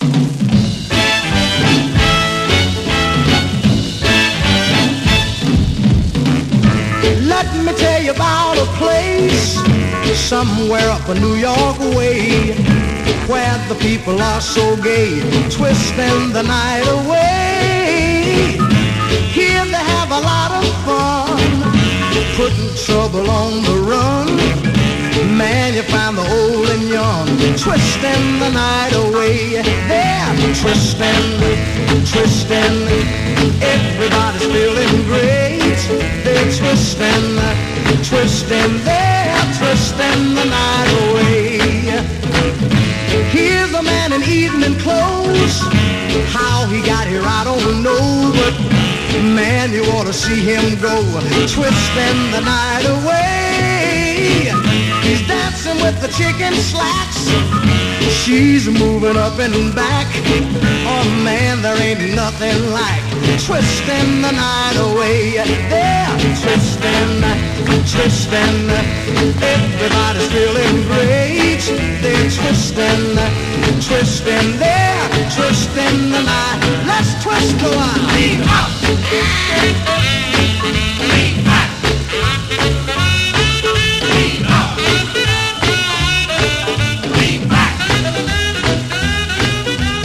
スレ非常に多いため試聴でご確認ください